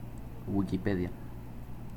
La palabra «Wikipedia», nombre propio acuñado por Larry Sanger a principios de 2001, es la contracción de wiki, una tecnología para crear sitios web colaborativos, procedente a su vez de wikiwiki, ‘rápido’ en hawaiano,[52] y encyclopedia, ‘enciclopedia’ en inglés.[53] Este nombre se pronuncia en inglés como
[ˌwɪkɨˈpiːdi.ə] o AFI: [ˌwɪkiˈpiːdi.ə] (AFI).
Es-Wikipedia.ogg.mp3